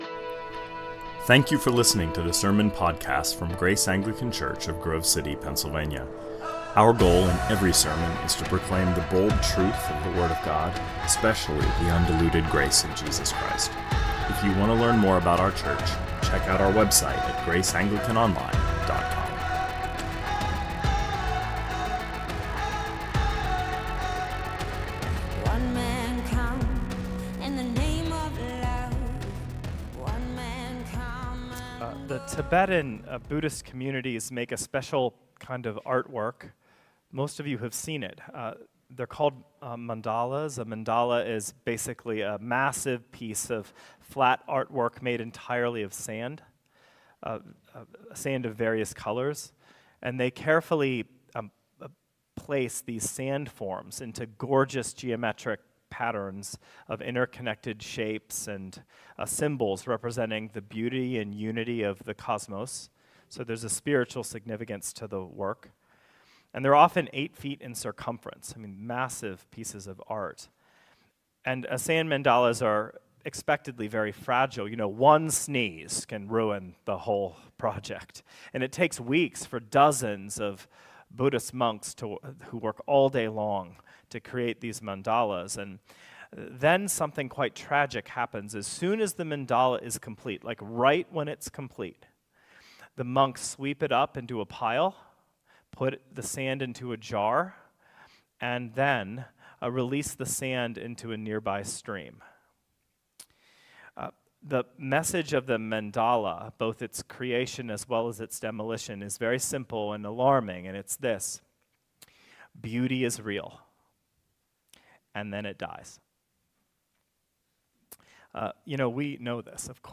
2024 Sermons